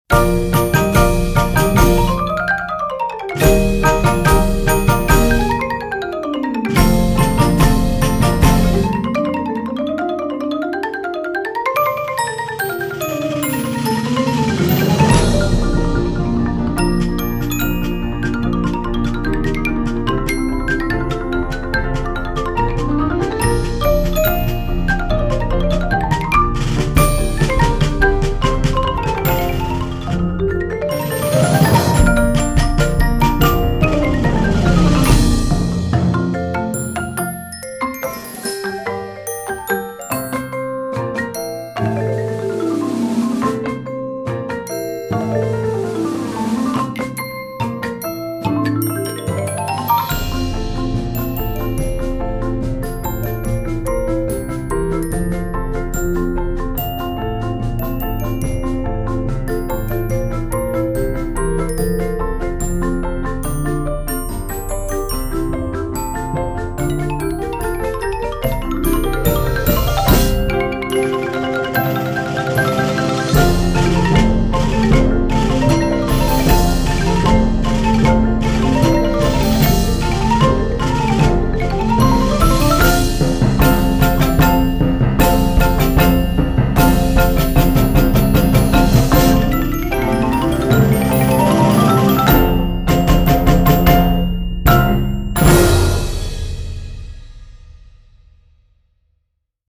Voicing: 14-15 Percussion